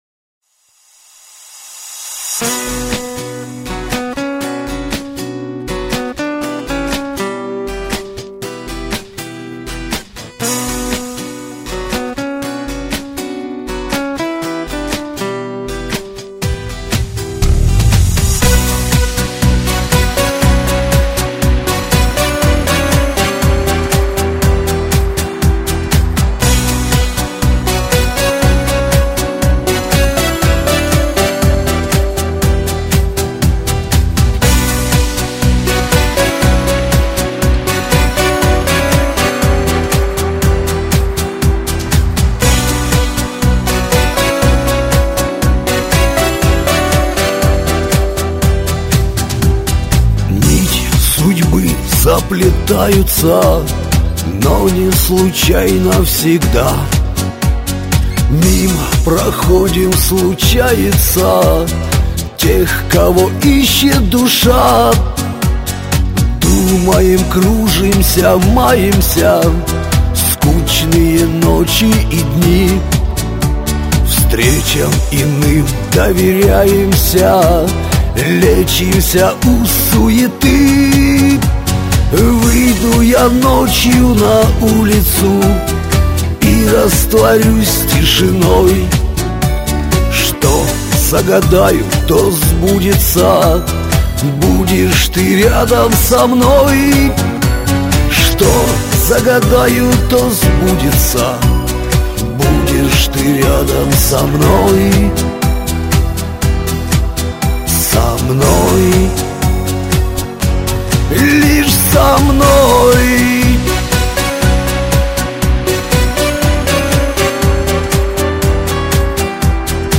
Очень лиричный!